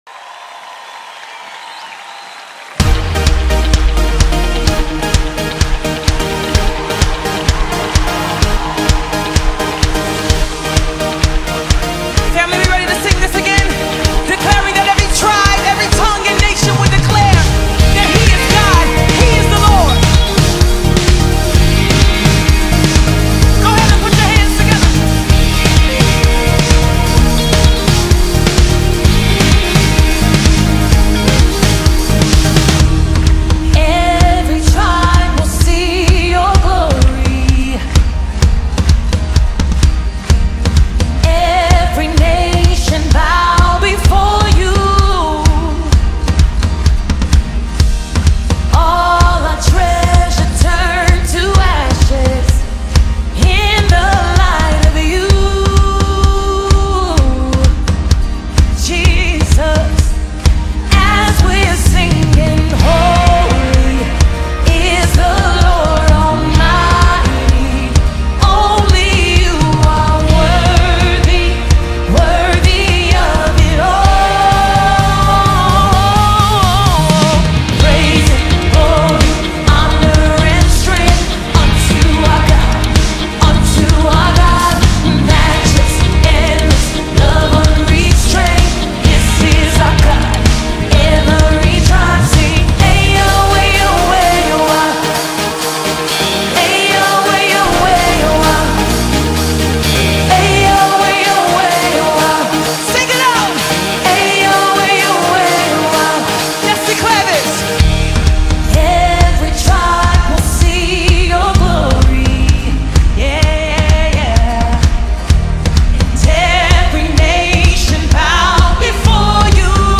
285 просмотров 454 прослушивания 12 скачиваний BPM: 124